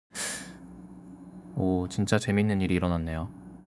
웃음이 안 나와요.
nonverbal/07_clone_korean_laughter — 클로닝+한국어+[laughter] 조합, 웃음 미발화
nonverbal_07_clone_korean_laughter.wav